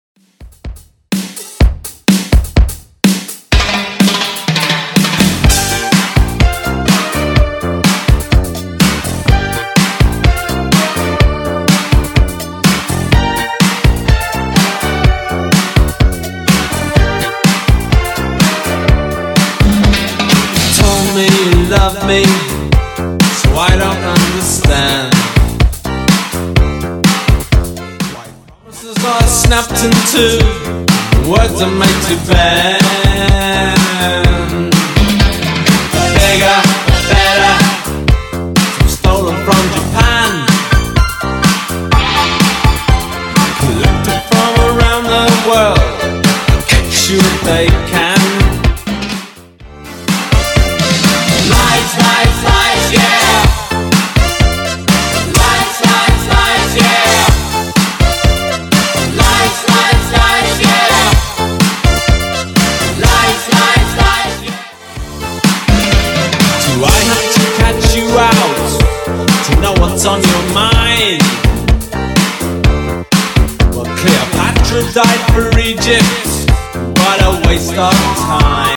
Genre: 80's
BPM: 122